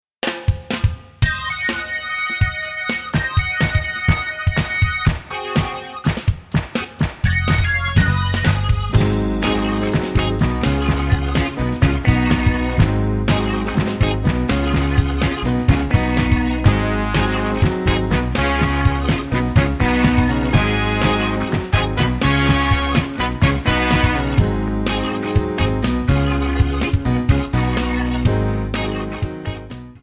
Ab
Backing track Karaoke
Pop, Disco, 1970s